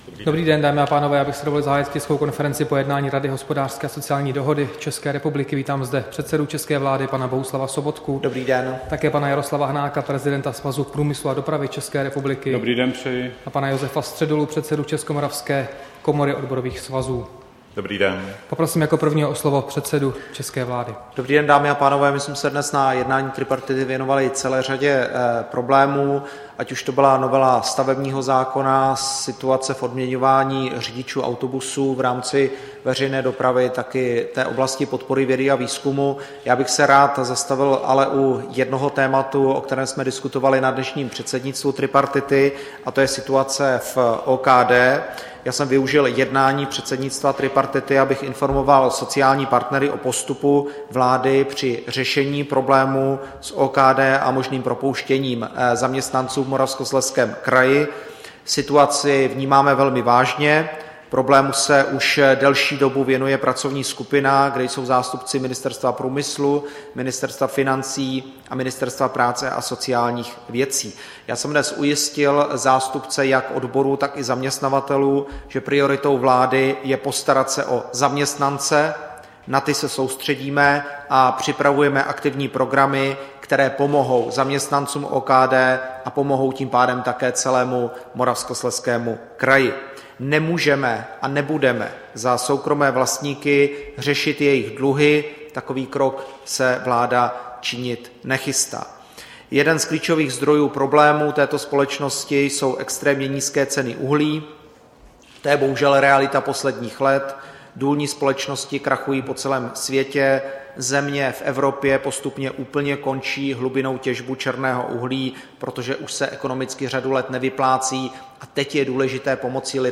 Tisková konference po jednání tripartity, 29. dubna 2016